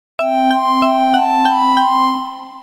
SE（アイテムゲット）
アイテムゲットしたときなどのSEです。